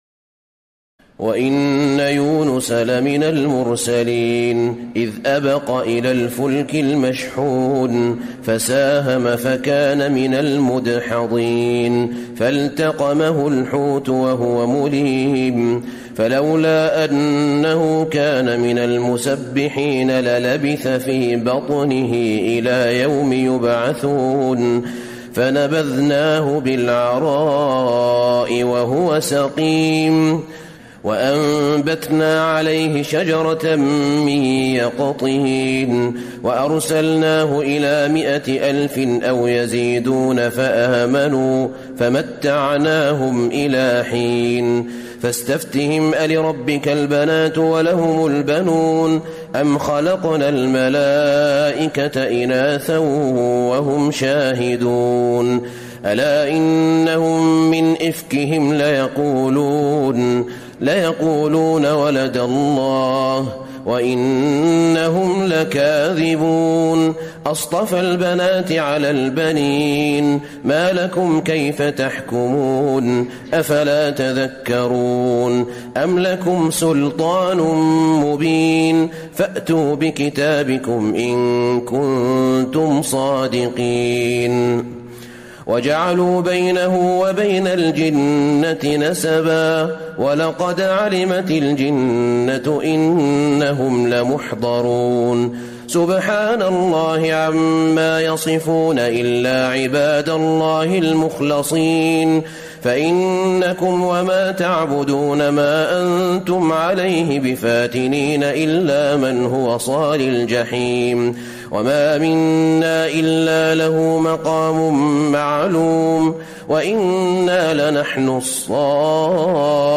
تراويح ليلة 22 رمضان 1434هـ من سور الصافات (139-182) وص و الزمر (1-31) Taraweeh 22 st night Ramadan 1434H from Surah As-Saaffaat and Saad and Az-Zumar > تراويح الحرم النبوي عام 1434 🕌 > التراويح - تلاوات الحرمين